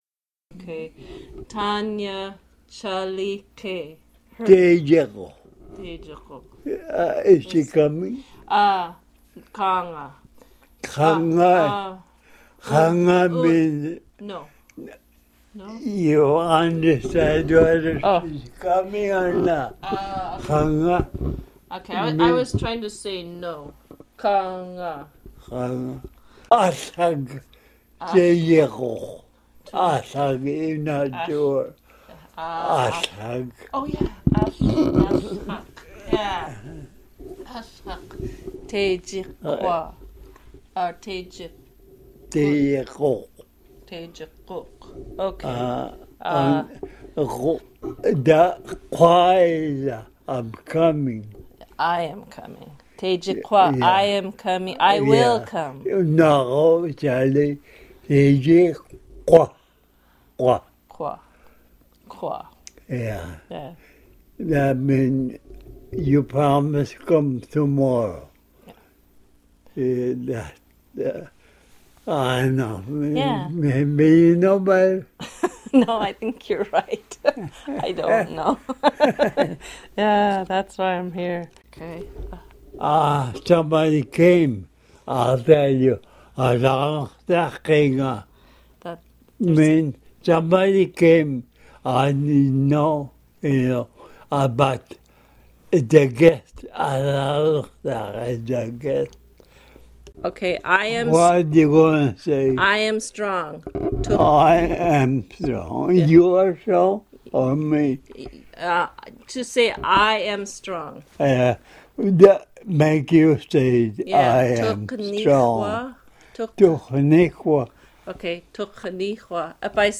Port Lions MA Session (stories, hunting, porpoise game, his mother) | Alutiiq Museum Collections
Access audio Summary: a bear hunting story, fish stories, marriages Description: a bear hunting story, fish stories, marriages Original Format: MiniDisc (AM470:296A) Migration: CD (AM470:296B) Location: Location Description: Kodiak, Alaska